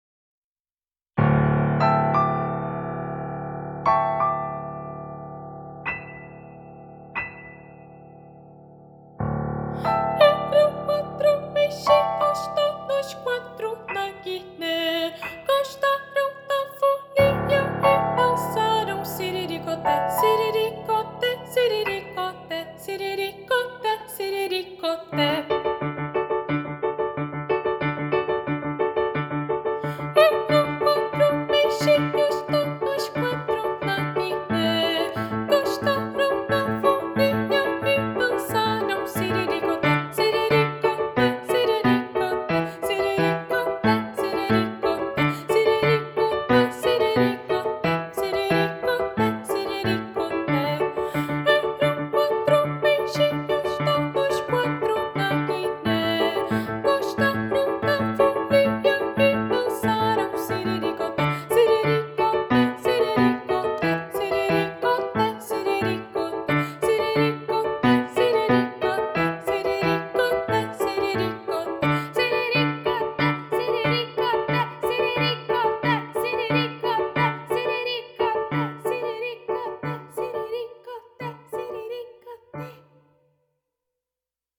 Voz Guia